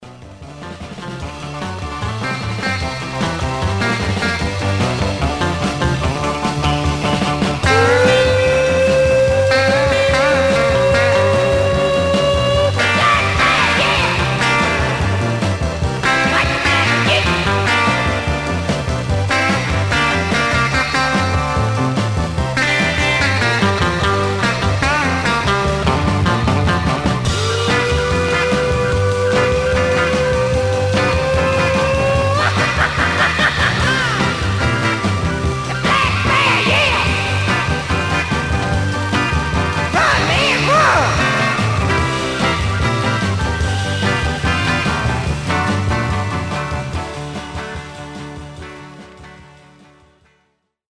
Surface noise/wear
Mono
R & R Instrumental